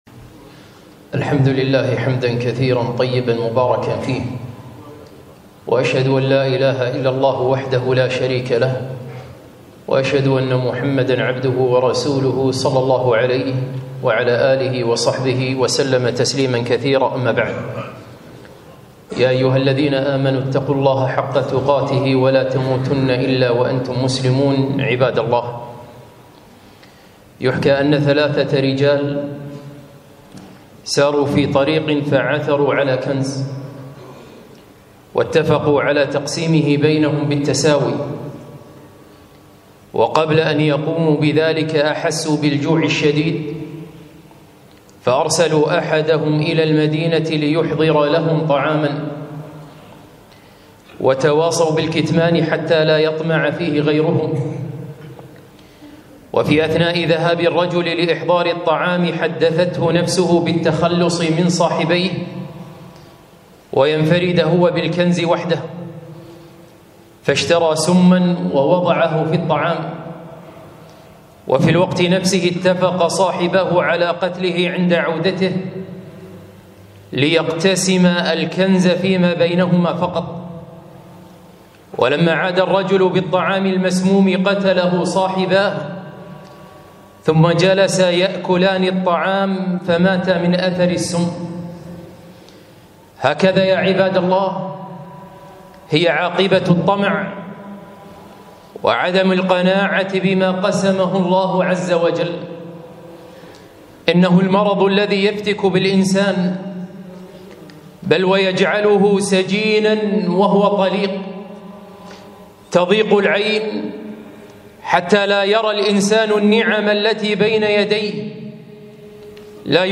خطبة - القناعة كنز لا يفنى